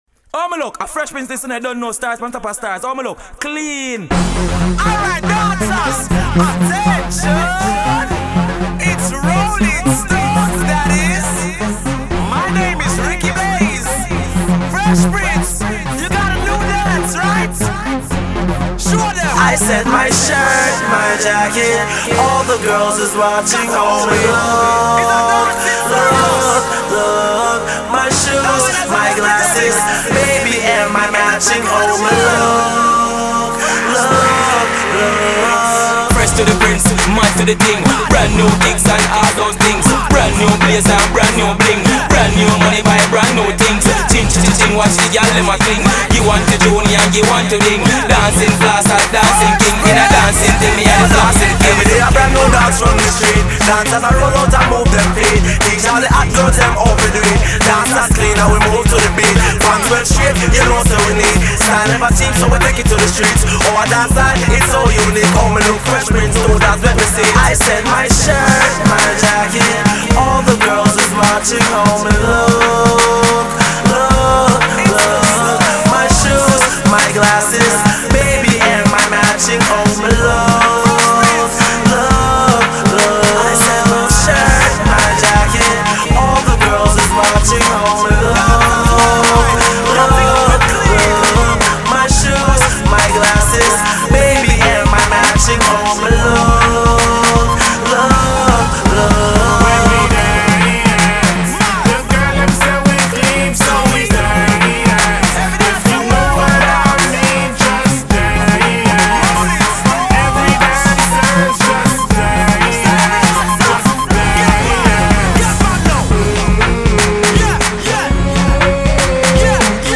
If you not a dancehall/reggae fan then move out the way.